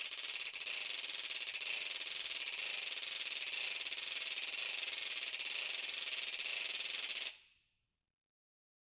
Ratchet1-Slow_v1_rr1_Sum.wav